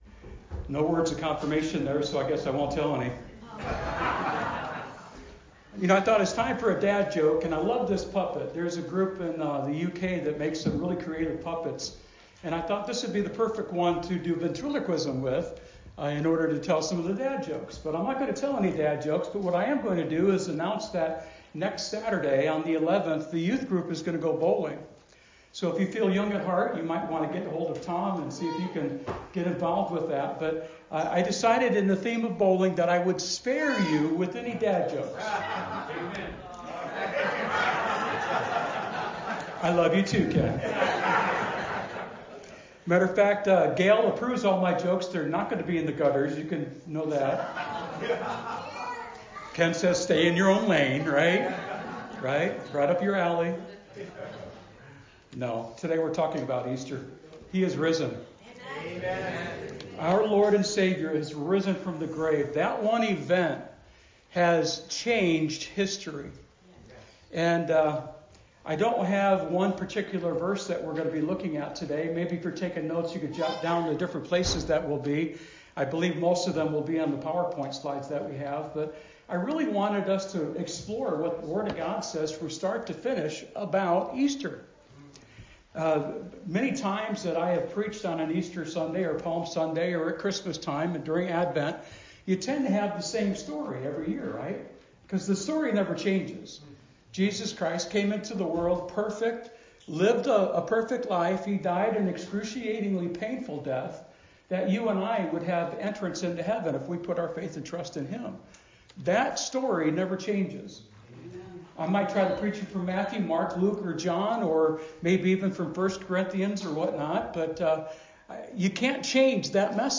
We covered a lot of ground from the Fall of Man (Genesis 3:15), to Noah and the flood, the Tower of Babel, the life of Abraham, the kings/priests/prophets of old, through Luke 24 (the Road to Emmaus), and ending in Revelation 21 – 22. The purpose of today’s Easter message was to show how Easter was not specifically a one-and-done event, but that it was a turning point in the War For Your Soul. If you listen to the sermon, this will make sense.